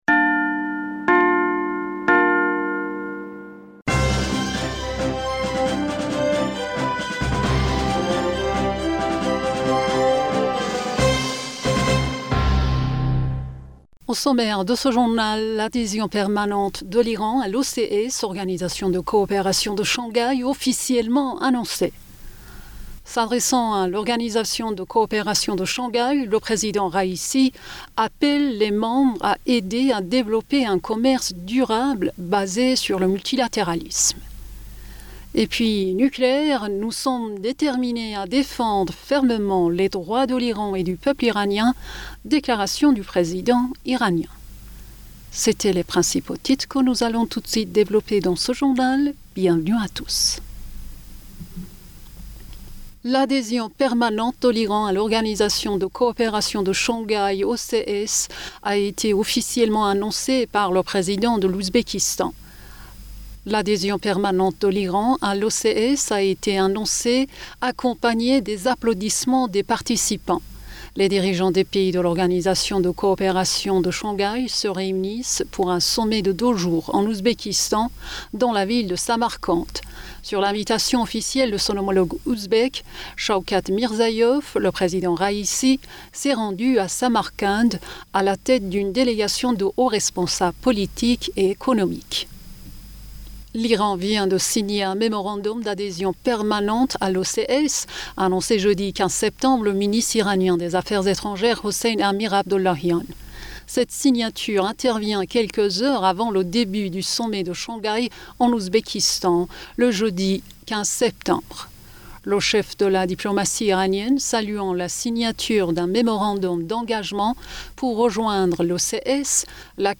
Bulletin d'information Du 16 Septembre